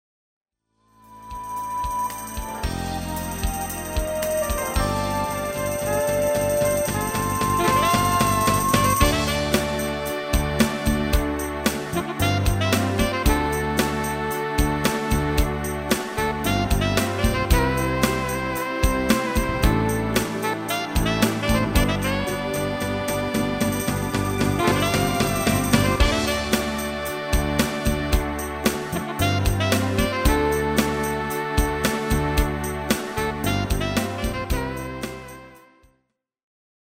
Demo/Koop midifile
Genre: Evergreens & oldies
- GM = General Midi level 1
- Géén vocal harmony tracks